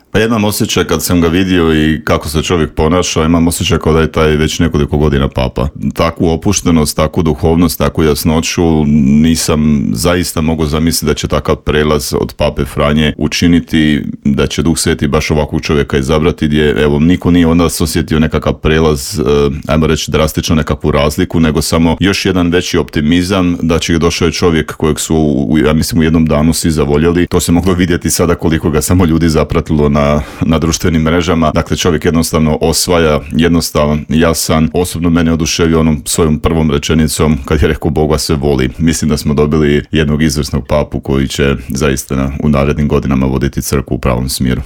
razgovarali smo u Intervjuu Media servisa